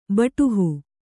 ♪ baṭuhu